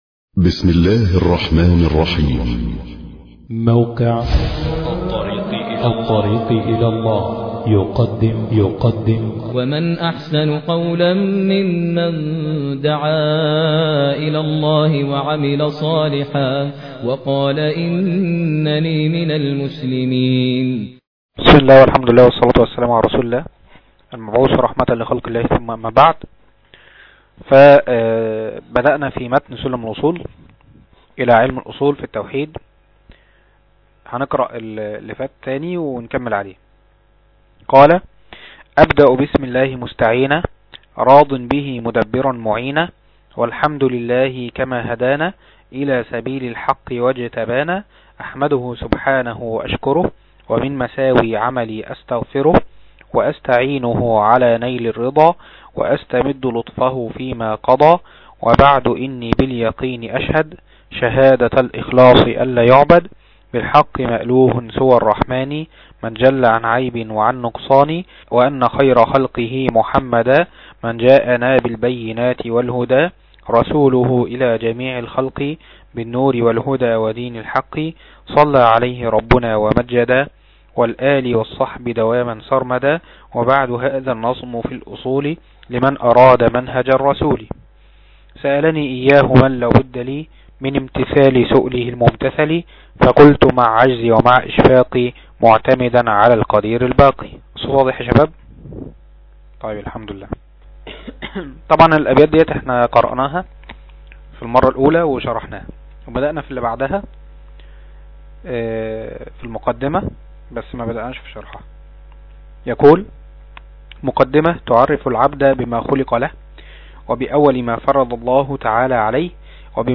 تفاصيل المادة عنوان المادة الدرس 2 شرح سلم الوصول تاريخ التحميل السبت 1 ديسمبر 2012 مـ حجم المادة 14.31 ميجا بايت عدد الزيارات 1,528 زيارة عدد مرات الحفظ 479 مرة إستماع المادة حفظ المادة اضف تعليقك أرسل لصديق